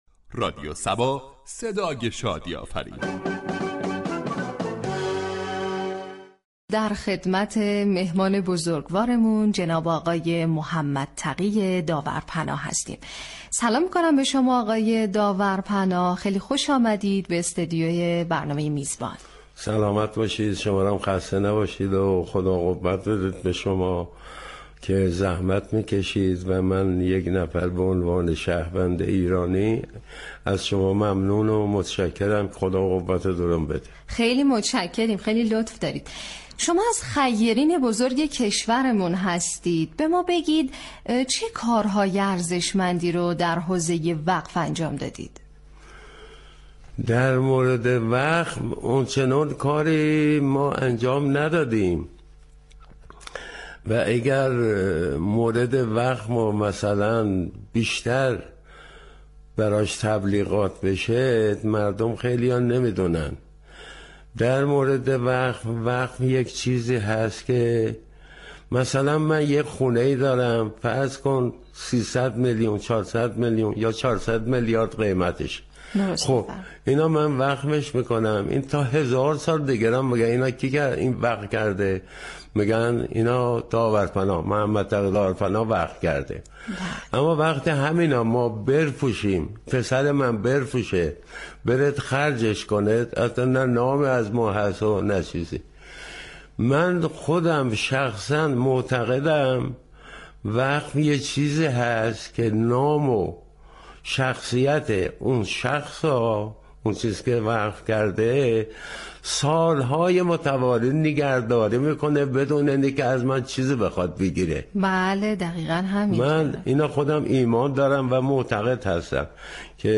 "میزبان" برنامه ای است كه با خیرین در حوزه های مختلف گفتگو مبكند